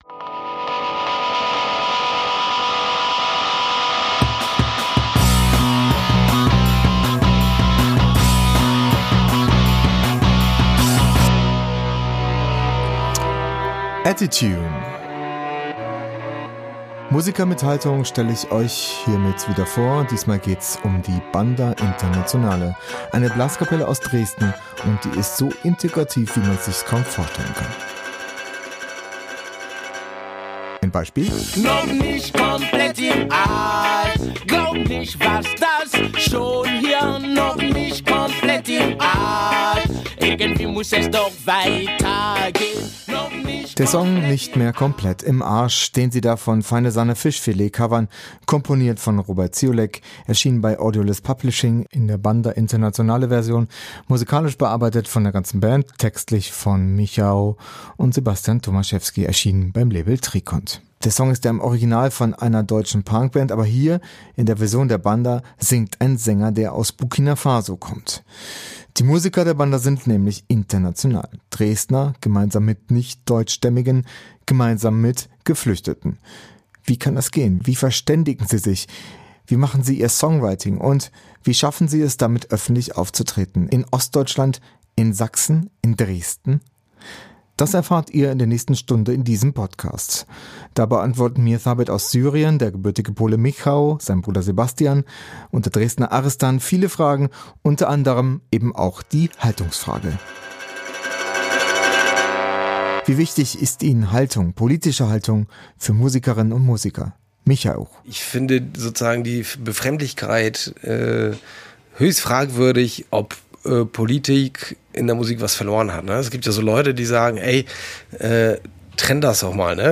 Beschreibung vor 6 Jahren Die Banda Internationale kommt aus Dresden und hat dort über viele Jahre als Blaskapelle auf Demonstrationen gegen Rechte gespielt. 2015 dann, bei der sogenannten "Flüchtlingskrise", waren sie natürlich musikalisch umso mehr gefragt.